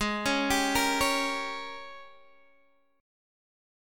AbM7sus2sus4 Chord